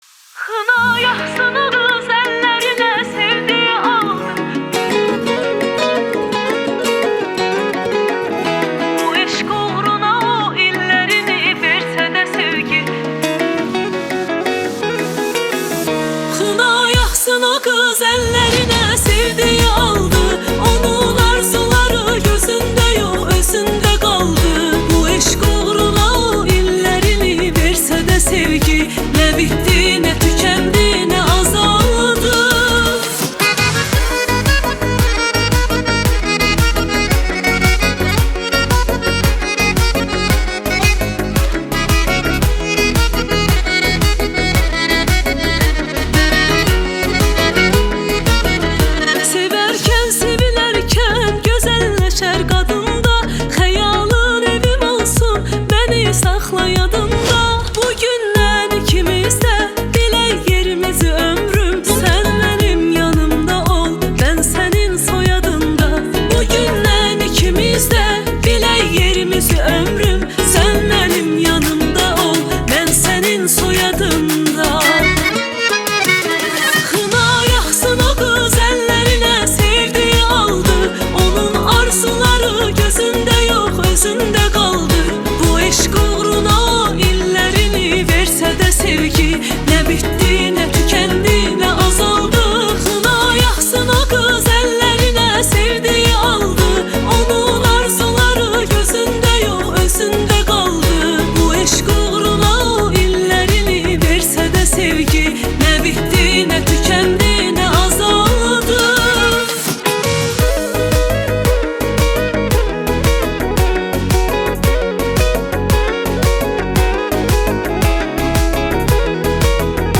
موزیک آذربایجانی
En Güzel Pop Müzikler Türkçe + Yeni şarkılar indir